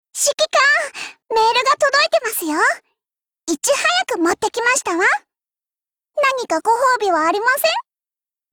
贡献 ） 协议：Copyright，人物： 碧蓝航线:小可畏语音 您不可以覆盖此文件。